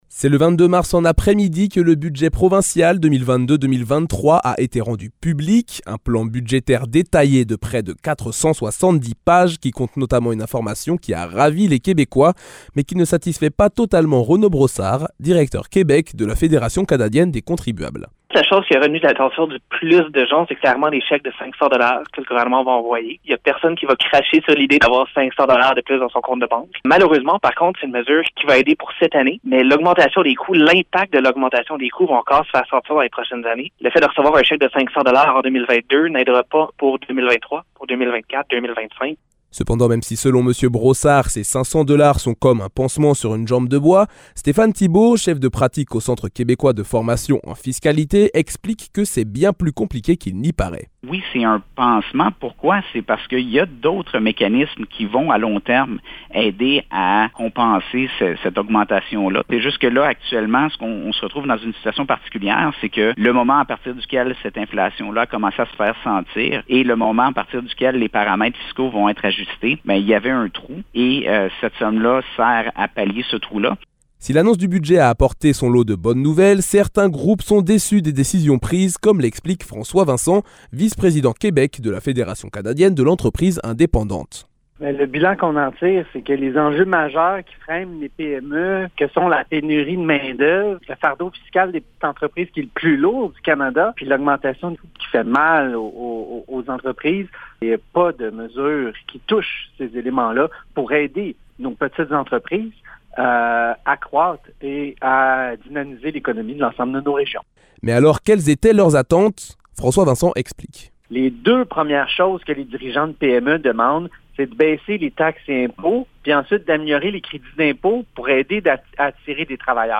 [Reportage] Budget du Québec 2022 - 24 mars 2022